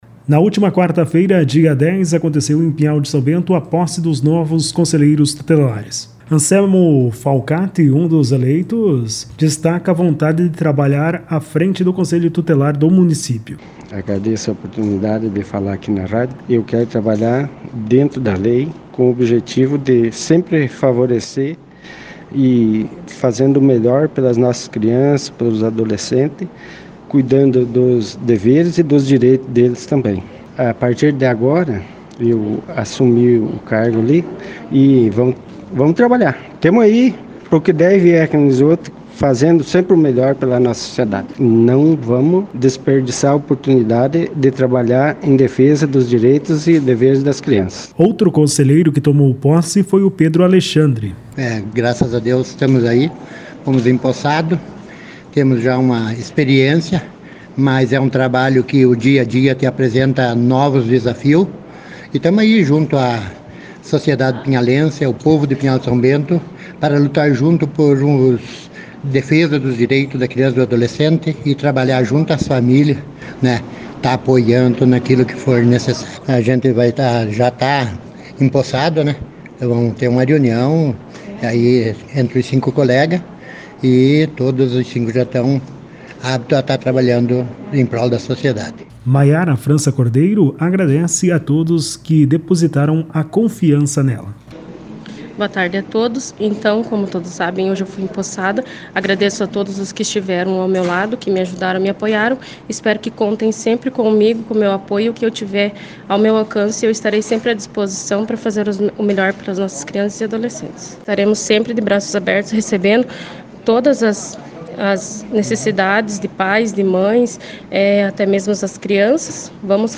O jornalismo Ampére AM e Interativa FM, acompanhou o evento.